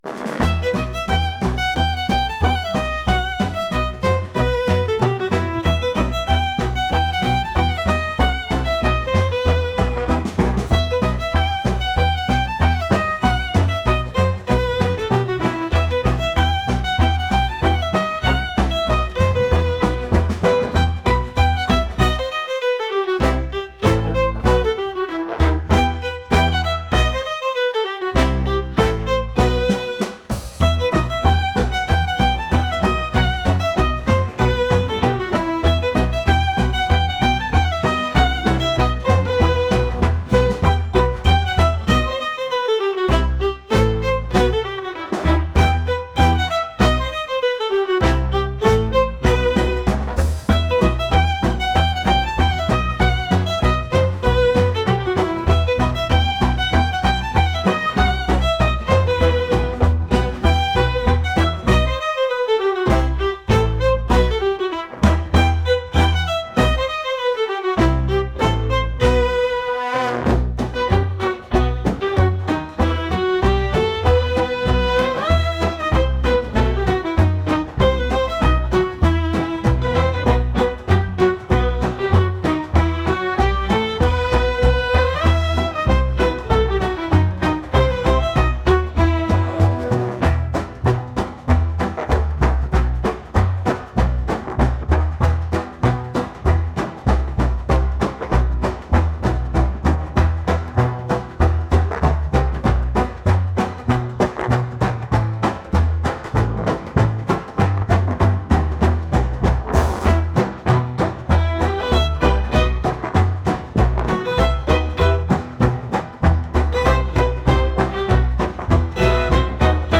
upbeat | jazz